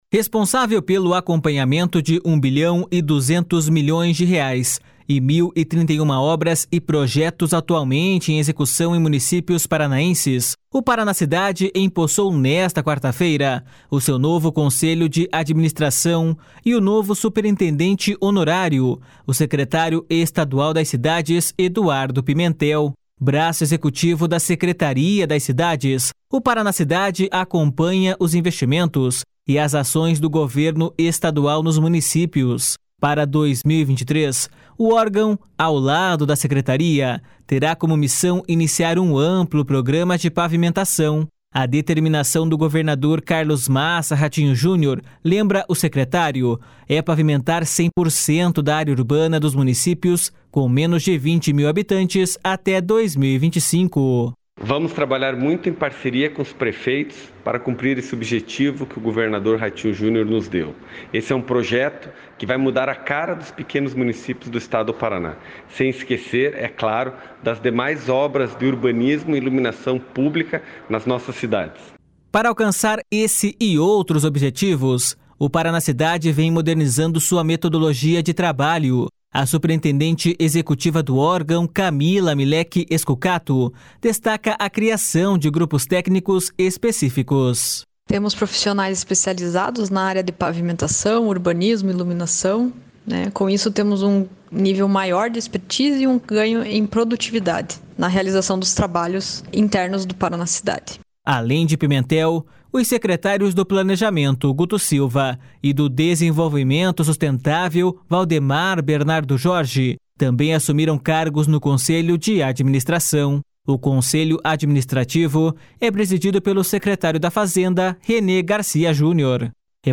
A determinação do governador Carlos Massa Ratinho Junior, lembra o secretário, é pavimentar 100% da área urbana dos municípios com menos de 20 mil habitantes até 2025.// SONORA EDUARDO PIMENTEL.//
Para alcançar esse e outros objetivos, o Paranacidade vem modernizando sua metodologia de trabalho. A superintendente executiva do órgão, Camila Mileke Scucato, destaca a criação de grupos técnicos específicos.// SONORA CAMILA MILEKE SCUCATO.//